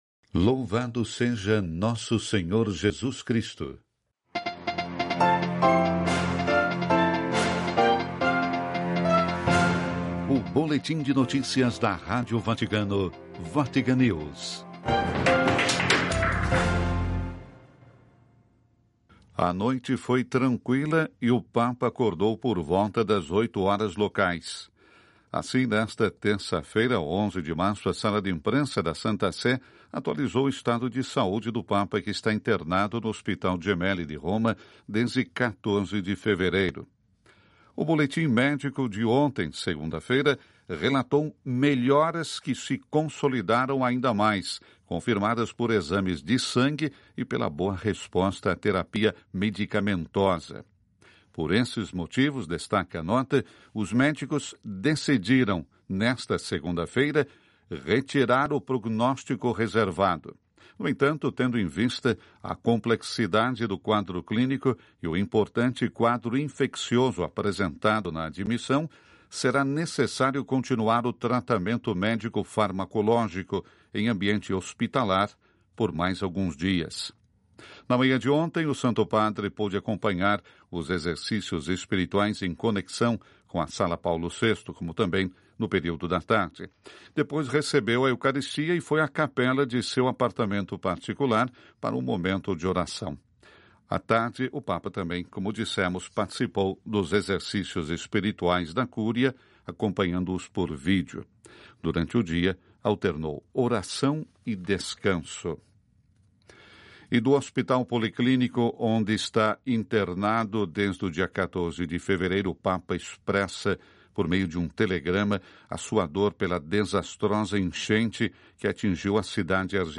Sem a necessidade de instalar ou se inscrever Noticiário (11:00 CET).